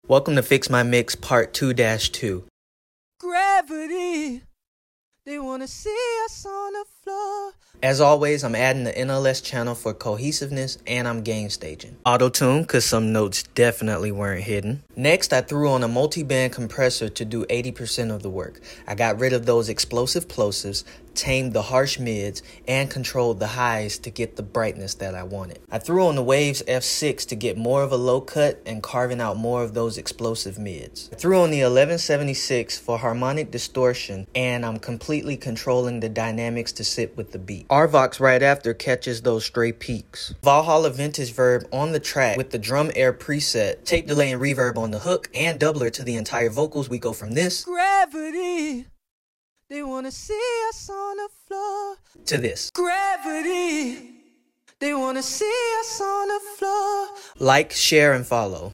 Can I take a $60 discontinued mic and make it sound like a quality studio recording?🤔